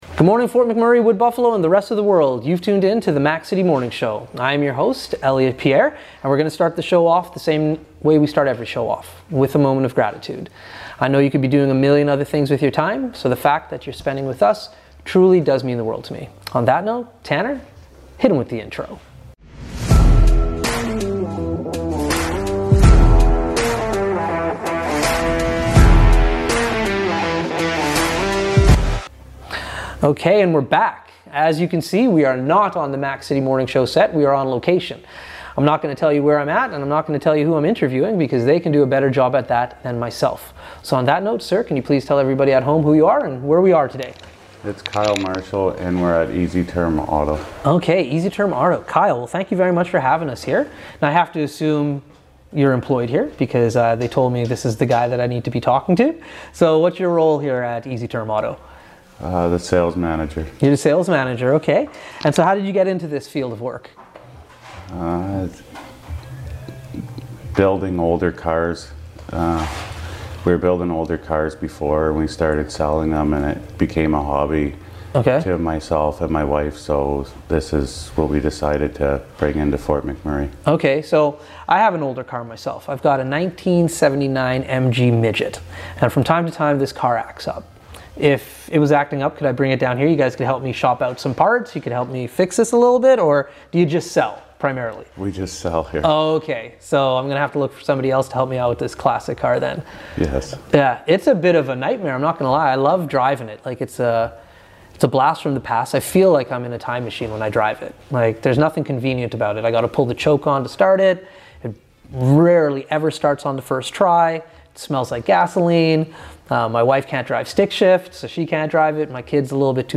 The Mac City Morning Show is on location today